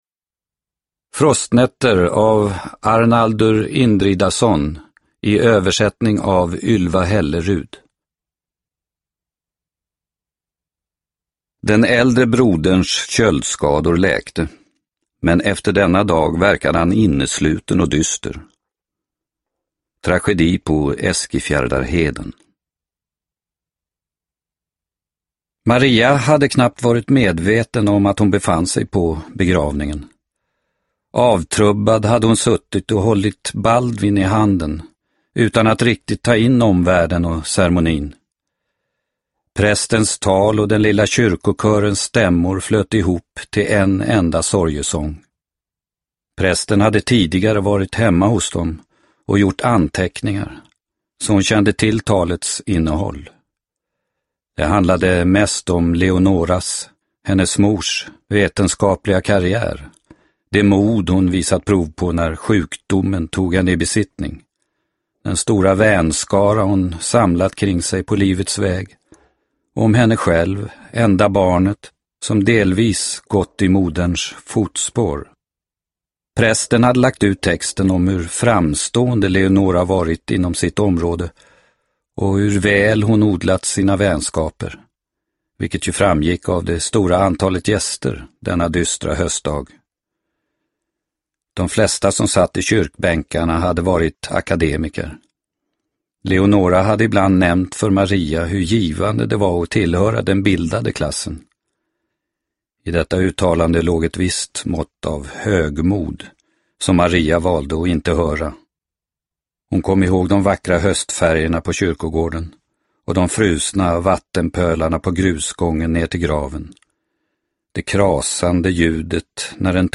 Frostnätter – Ljudbok – Laddas ner